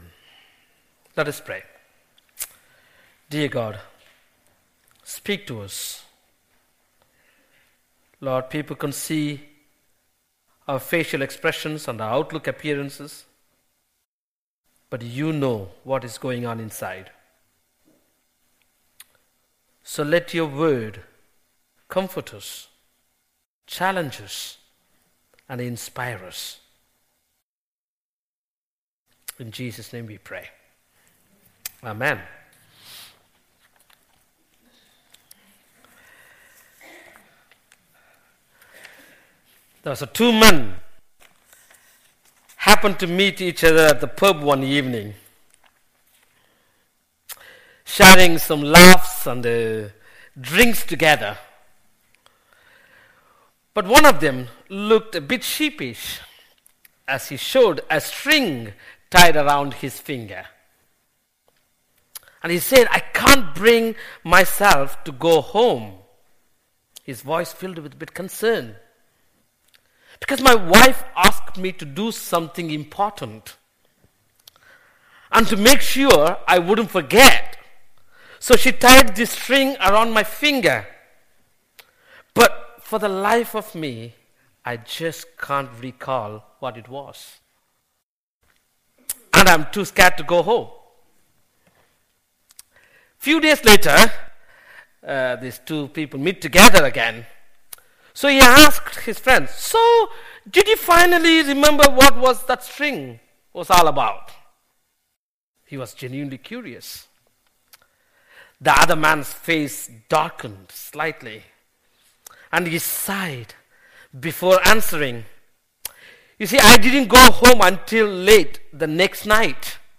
An audio version of the sermon is also available.